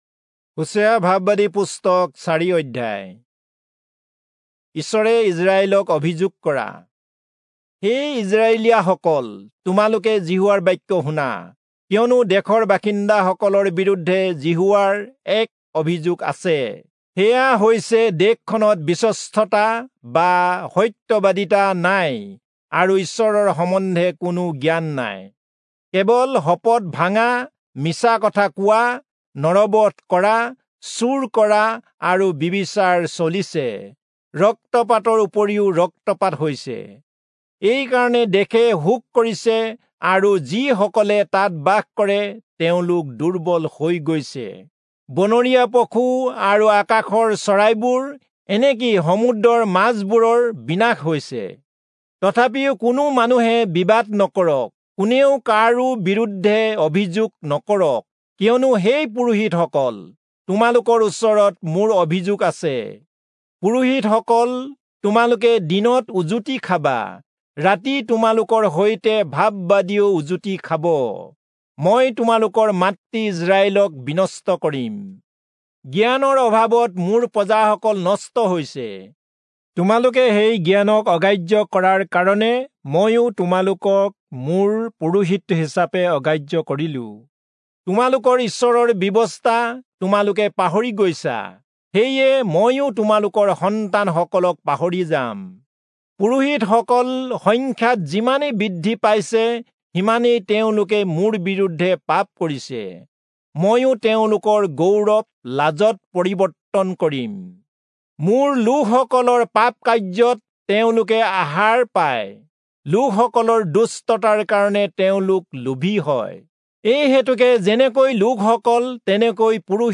Assamese Audio Bible - Hosea 9 in Gntbrp bible version